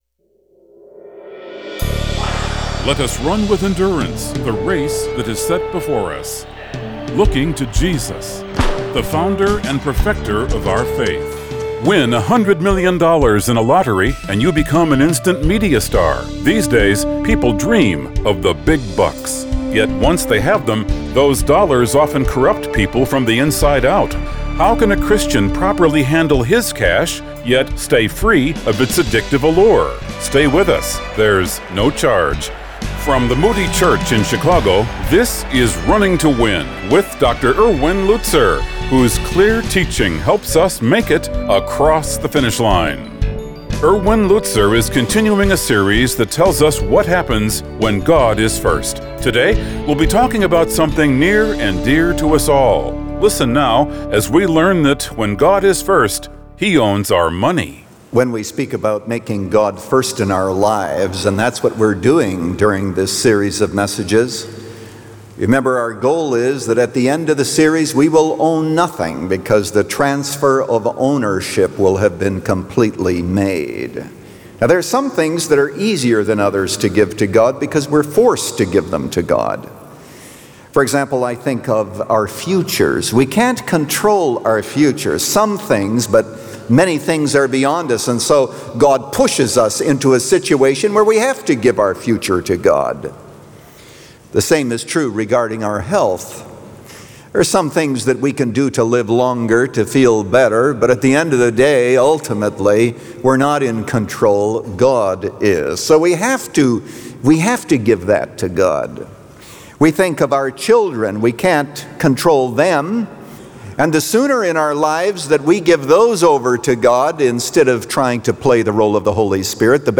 He Owns Our Money – Part 1 of 3 | Radio Programs | Running to Win - 15 Minutes | Moody Church Media